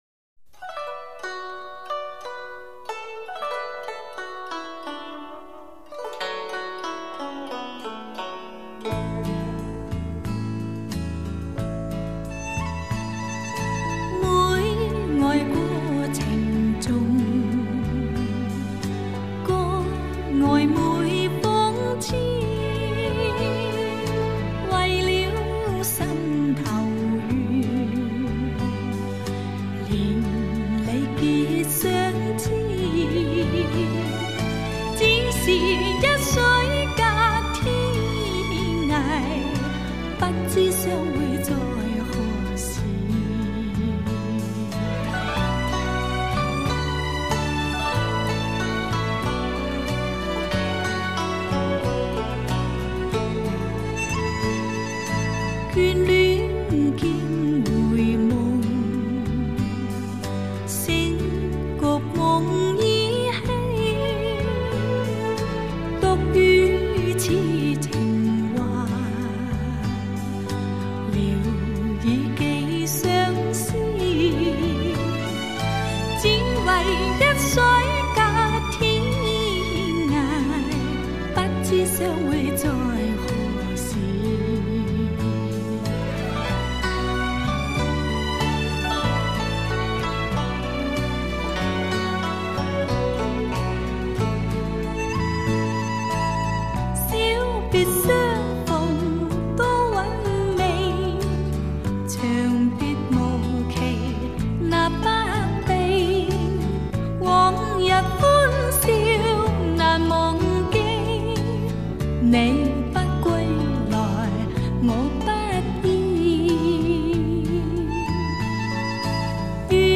这是整张唱片中音色最美的，只要歌者一开声，谁都会被那清甜而迷人的音色所吸引